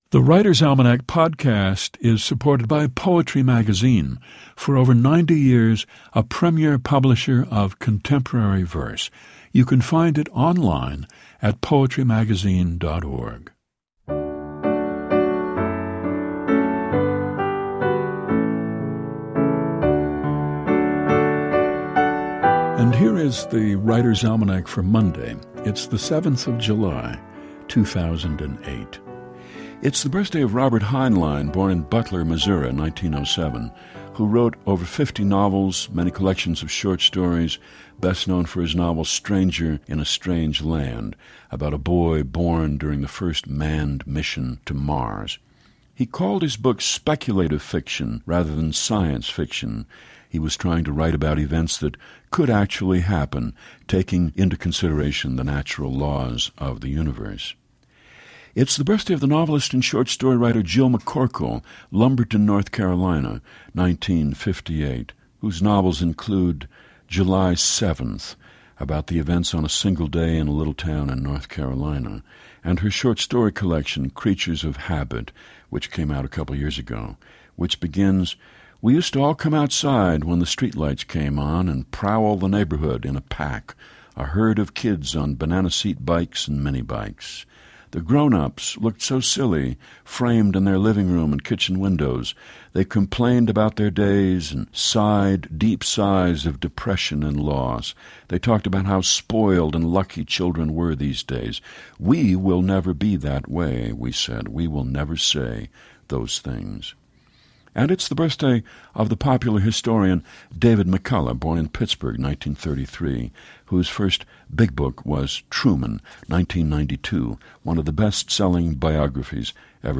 Each day, The Writer's Almanac features Garrison Keillor recounting the highlights of this day in history and reads a short poem or two.